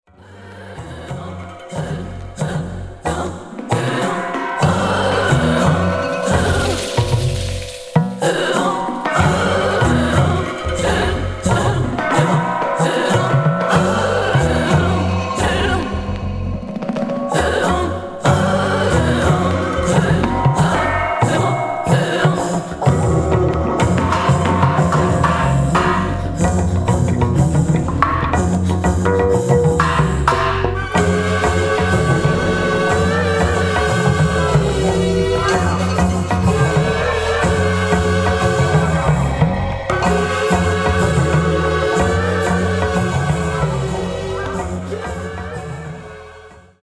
メタルパーカション系の打撃音も加わって全編ただごとじゃない雰囲気。
空想民俗　第四世界